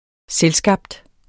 Udtale [ -ˌsgɑbd ]